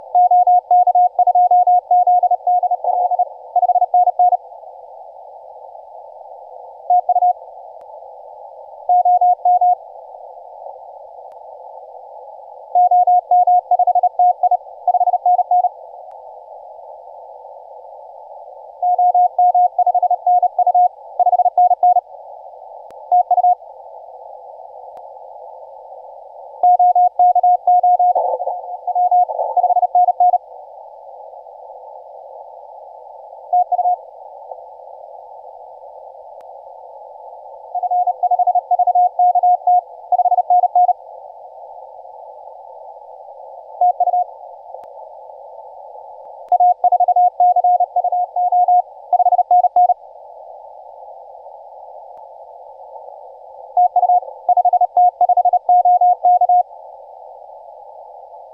5T5OK 20CW